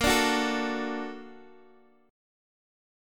D/Bb chord
D-Major-Bb-x,x,8,7,7,5.m4a